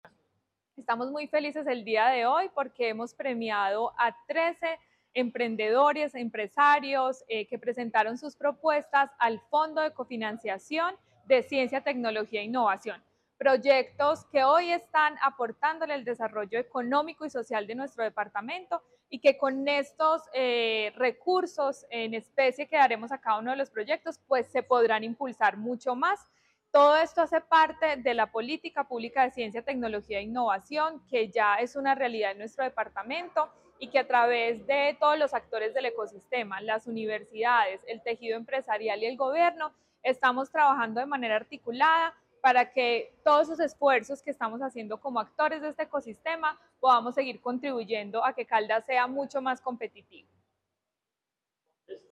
Daissy Lorena Alzate, secretaria de Desarrollo, Empleo e Innovación